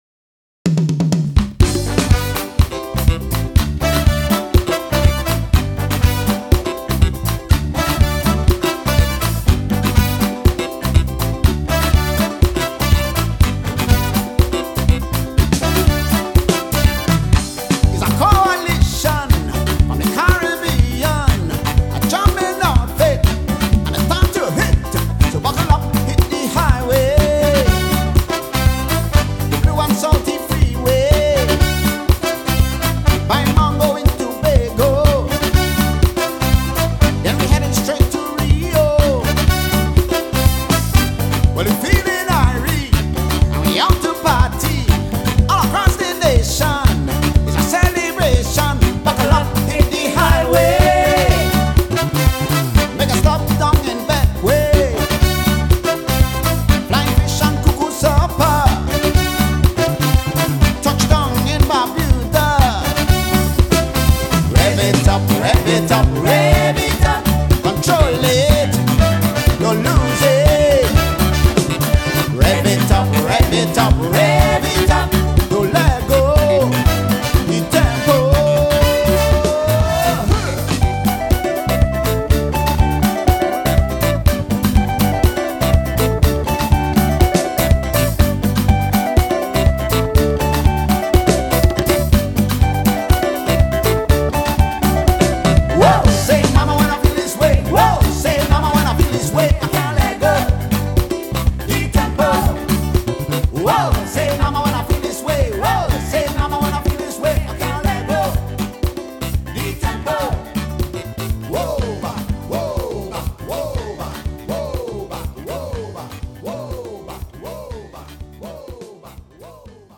TThe Trio: Bass, Guitar & Drums, self-contained vocals.
Soca, Calypso, Reggae, Compa, Zouk and African music.